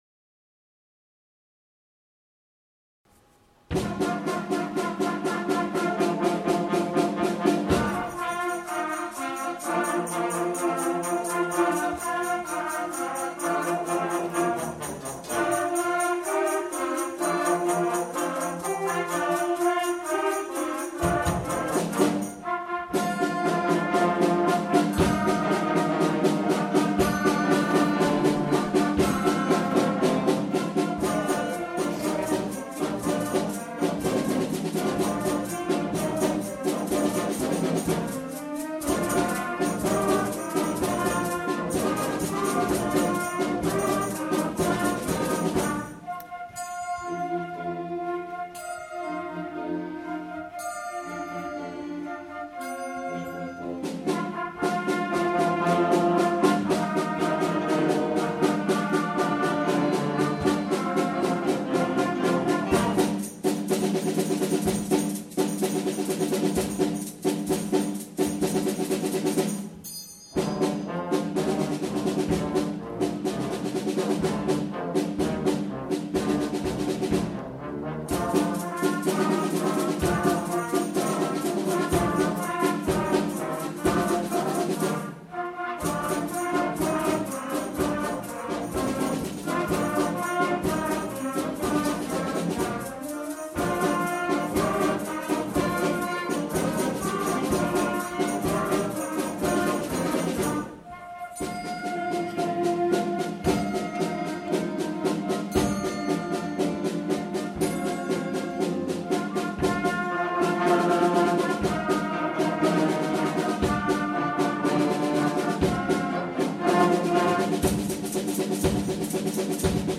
Junior Wind Band
Performed at the Autumn Concert, November 2014 at the Broxbourne Civic Hall. Presto Junkyard Jam